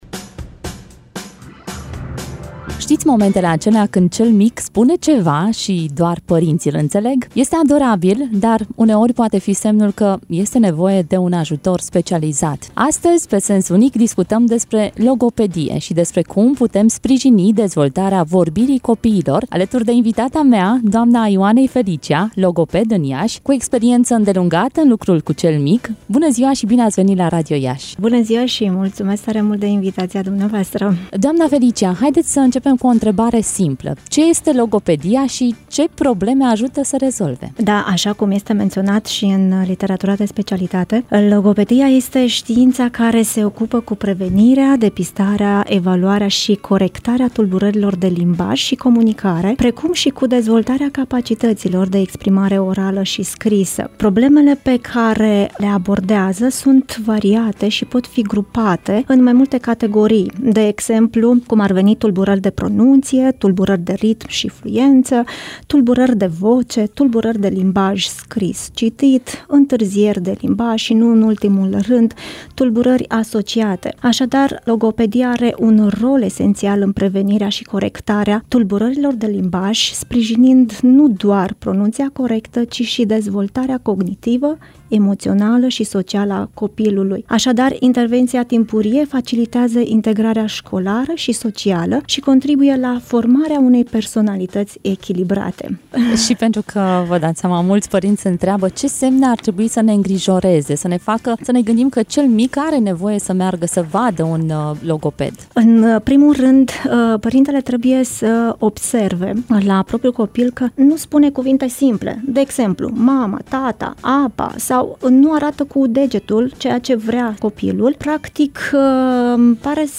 Primii pași în vorbire – interviu